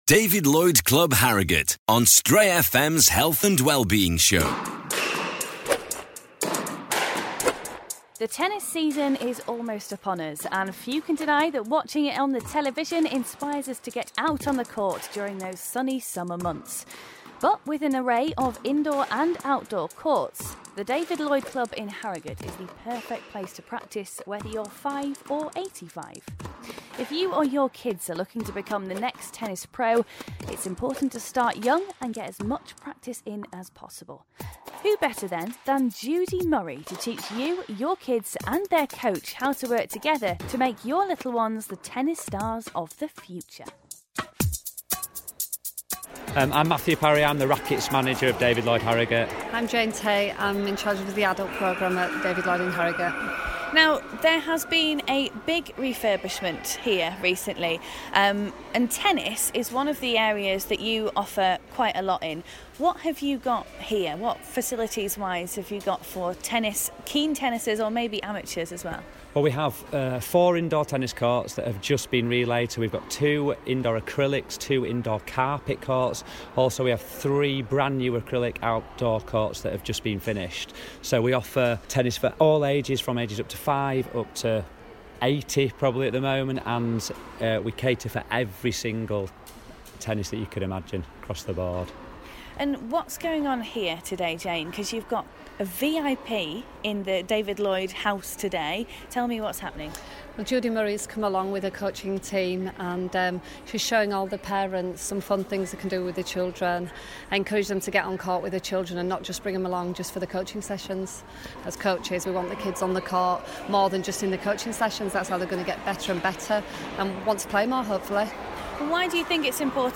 Judy Murray visted the David Lloyd Club in Harrogate for a workshop with parents and children on practising at home.